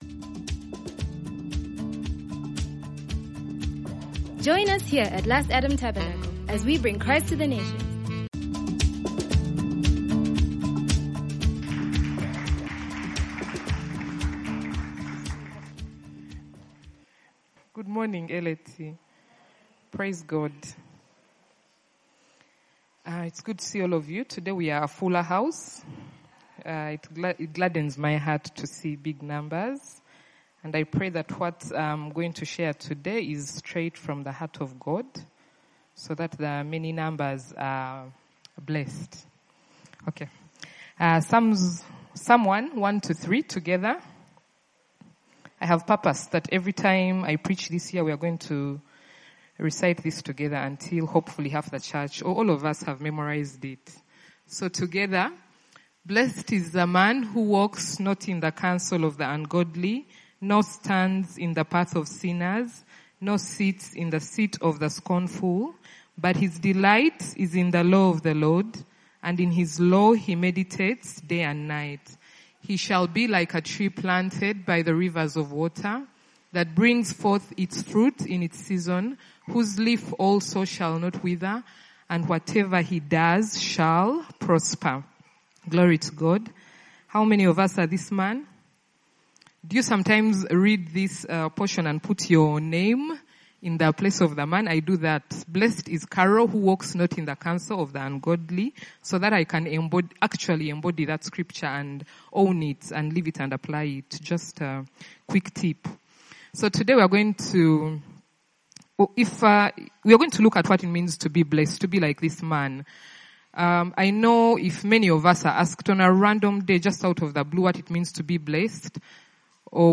As you listen to this Sunday's sermon, meditate on which areas you need to change your commitments and patterns to allow you to greatly delight in the Lord and to be prosperous just like the main in Psalm 1:1-3.